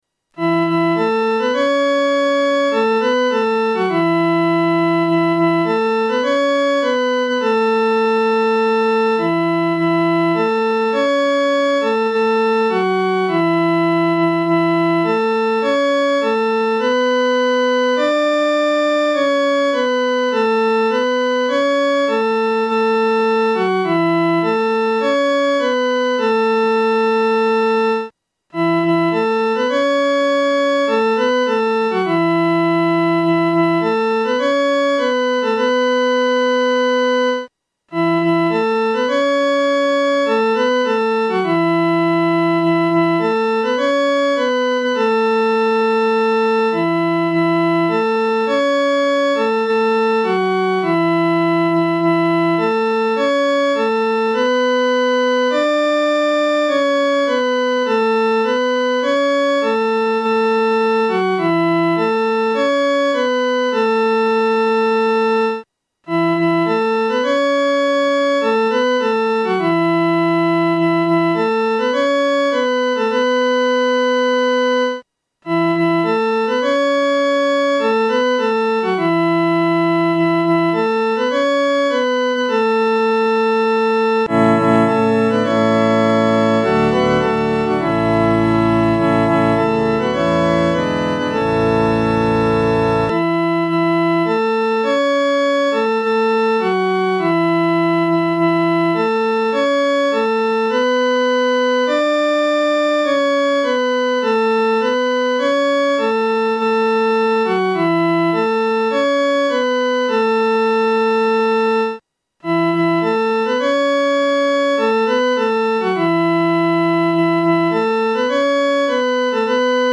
女高
本首圣诗由网上圣诗班 (环球）录制
《颂赞主圣名歌》的正歌曲调比较平稳，但副歌曲调兴奋、活跃。